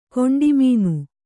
♪ koṇḍi mīnu